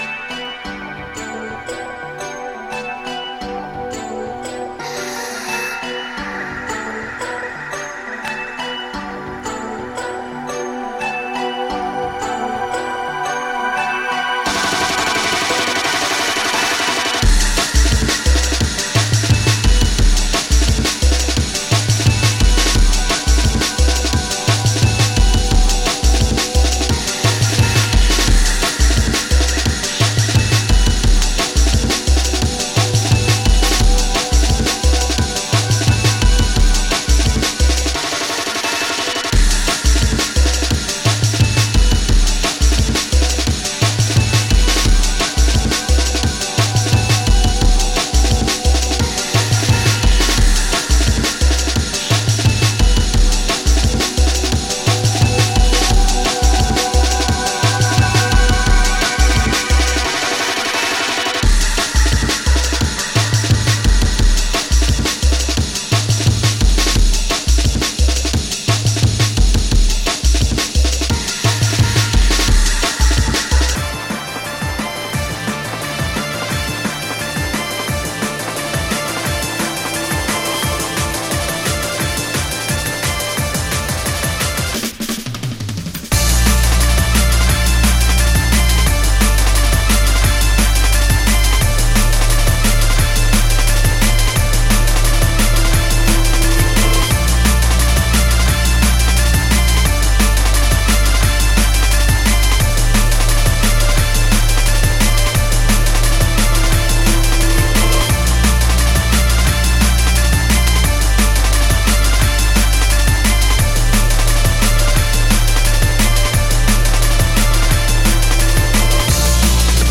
Category: Drum N Bass